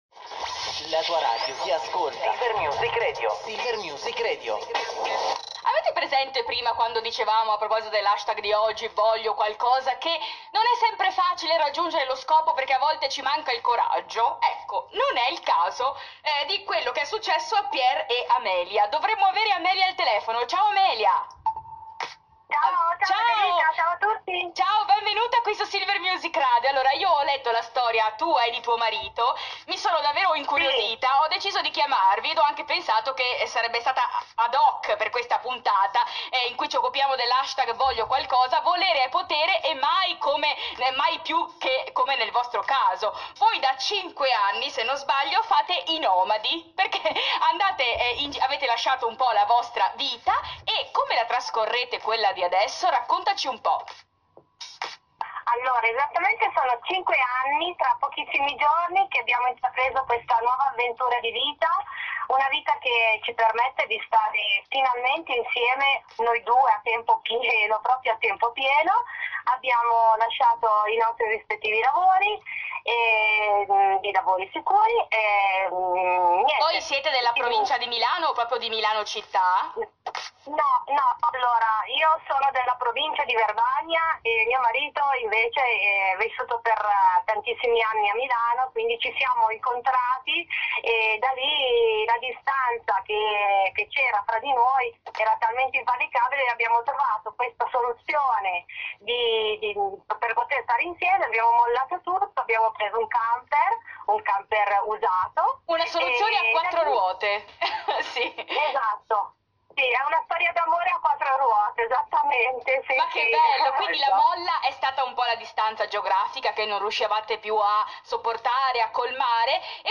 Interviste Radiofoniche
Intervista-Silver-Radio.mp3